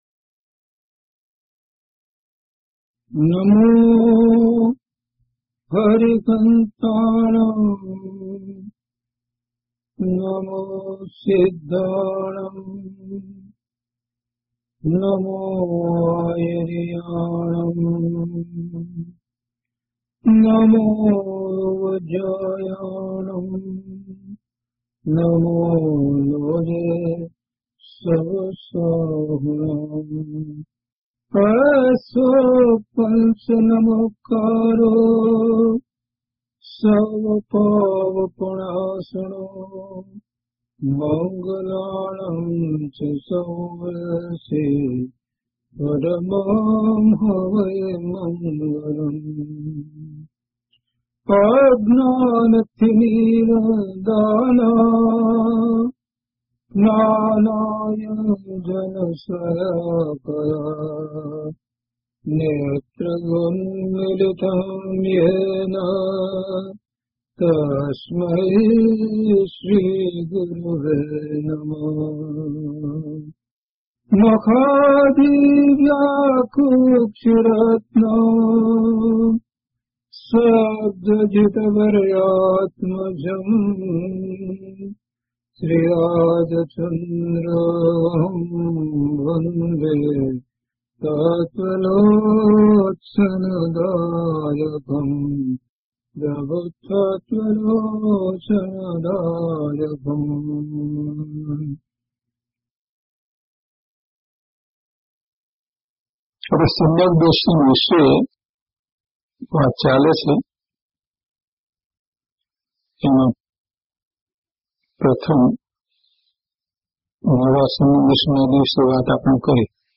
DHP077 Samyag Darshan (Chha Pad) part-2  - Pravachan.mp3